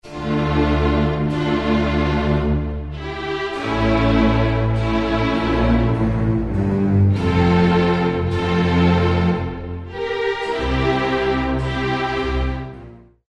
dark